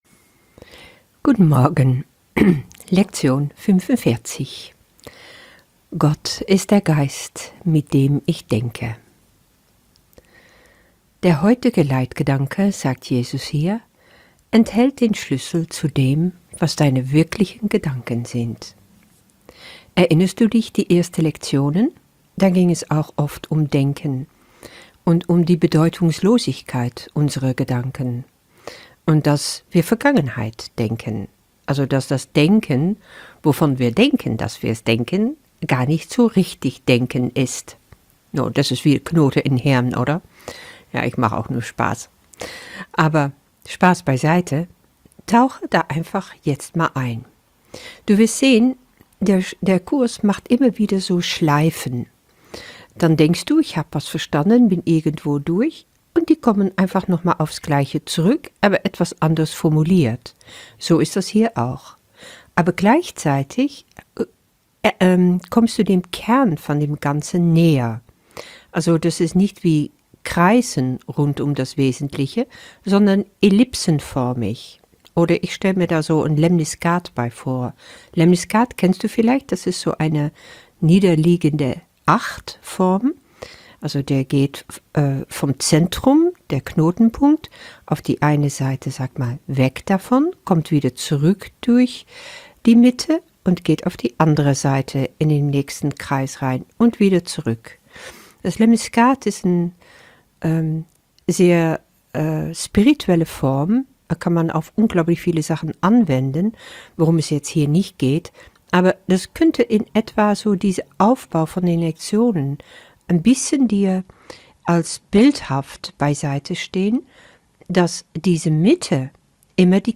Warte es ab, die Meditations-anleitung ist präzise und du brauchst sie nur befolgen.